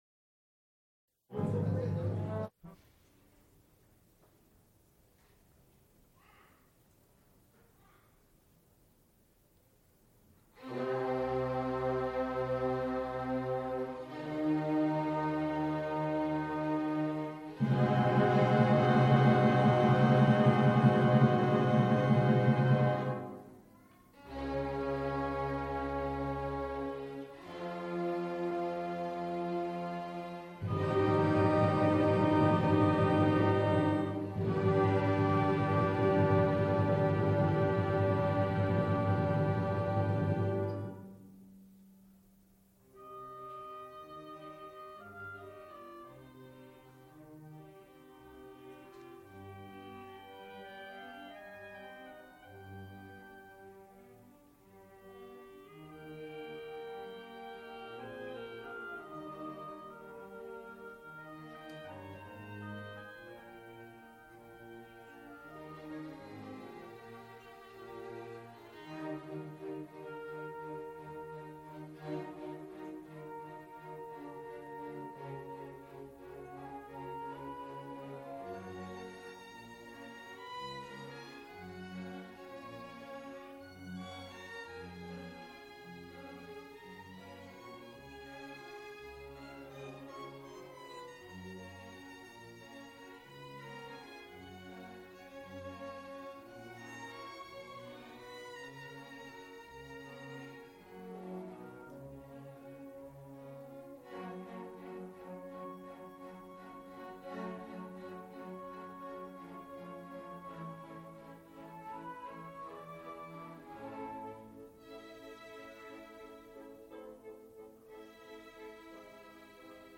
Recorded live January 31, 1978, Schenley Hall, University of Pittsburgh.
Genre musical performances